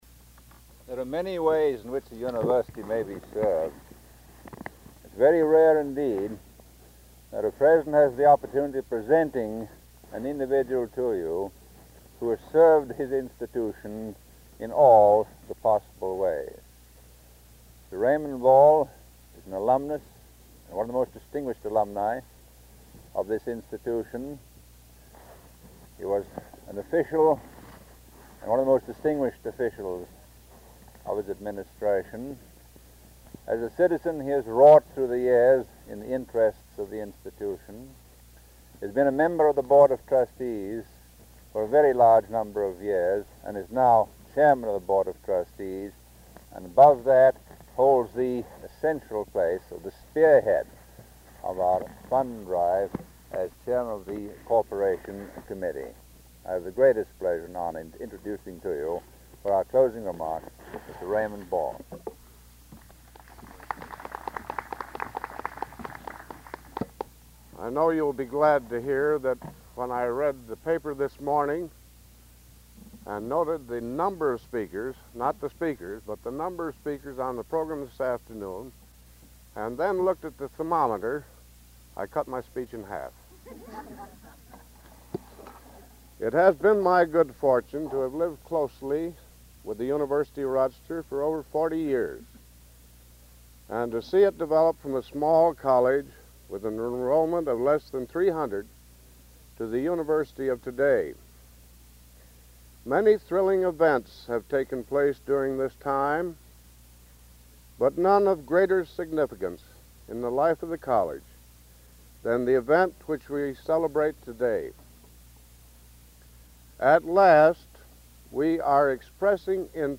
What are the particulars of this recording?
1952 Groundbreaking for Susan B. Anthony Hall and Spurrier Gymnasium